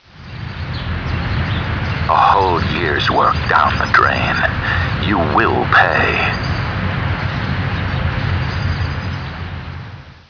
THE LONDON SUN & NEWS OF THE WORLD's confidential sources have discovered that a series of threatening and possibly revealing telephone calls are being received from a source or sources unknown who apparently have information on the whereabouts of Meg Townsend.
Written transcripts of audio clues (TXT files) are available for users without sound-cards -- but where possible, we recommend downloading the sound files, because the background sounds and audio subtleties can be helpful in formulating your theory!